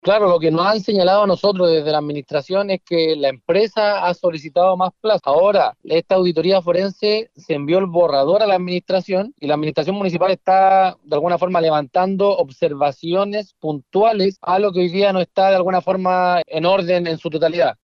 Por su parte, Vicente Celedón, concejal de la comuna puerto, señaló que ha sido la empresa la que ha solicitado mayor plazo, mientras que el municipio se encuentra realizando observaciones a esta versión parcial del informe forense.